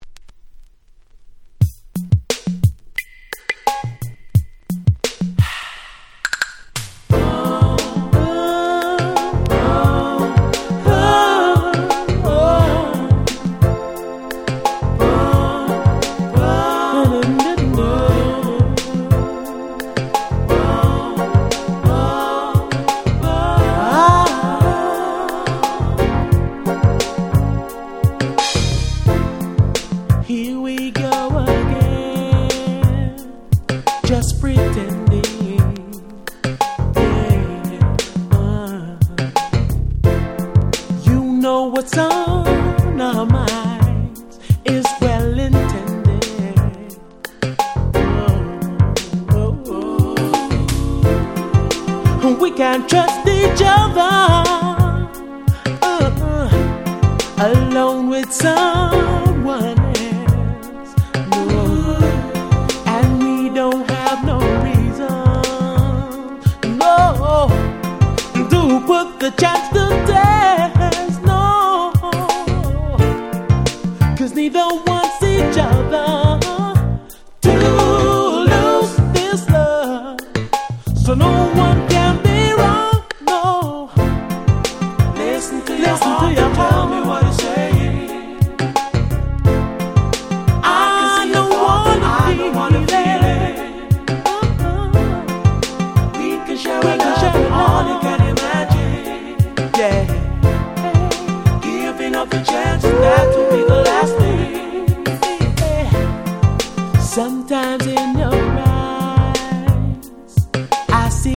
90' Nice UK R&B / UK Street Soul !!
レゲエ感は全くと言って良い程に感じられませんが(笑)、ブラコン感満載の凄く良い曲です！
(Vocal)